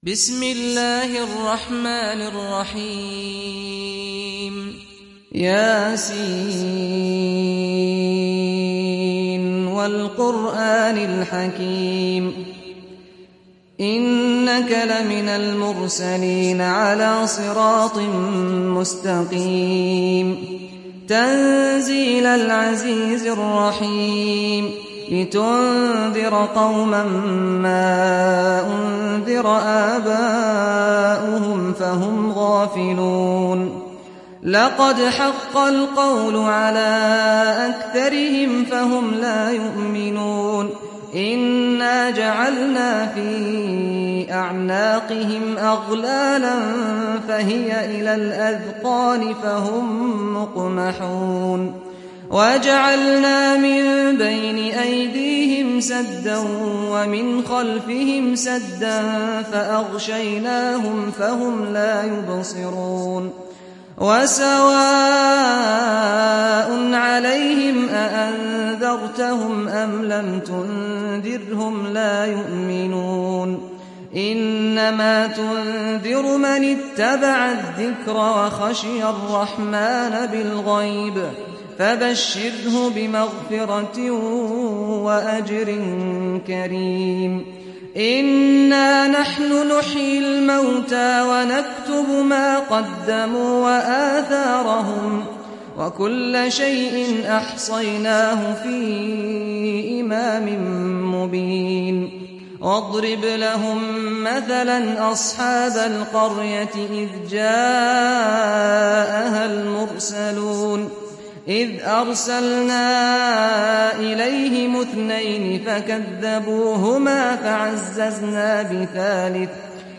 تحميل سورة يس mp3 بصوت سعد الغامدي برواية حفص عن عاصم, تحميل استماع القرآن الكريم على الجوال mp3 كاملا بروابط مباشرة وسريعة